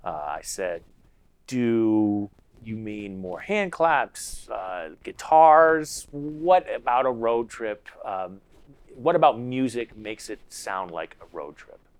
Once we got the problems with our data straightened out and trained the network for a couple days on a NVIDIA K80 GPU, we were ready to try it out removing rustle from some pretty messy real-world examples:
de-rustle-3_proc.wav